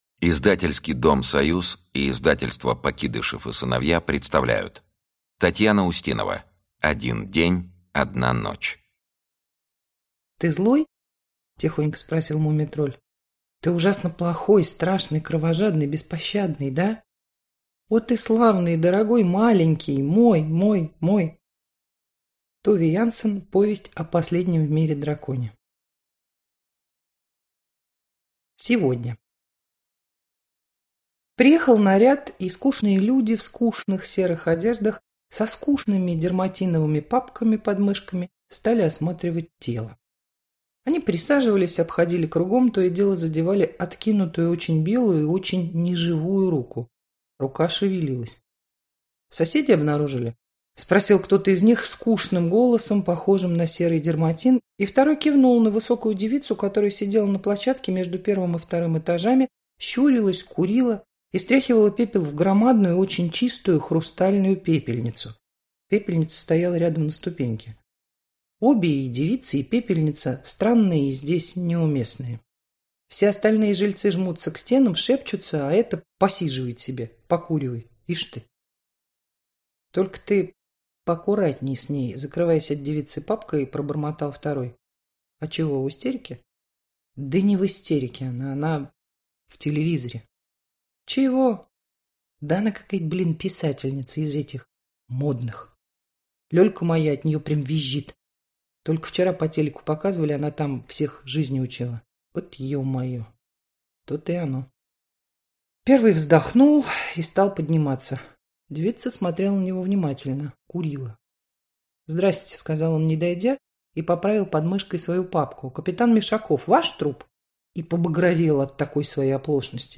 Аудиокнига Один день, одна ночь - купить, скачать и слушать онлайн | КнигоПоиск